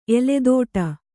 ♪ eledōṭa